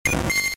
Cri de Mélofée K.O. dans Pokémon Diamant et Perle.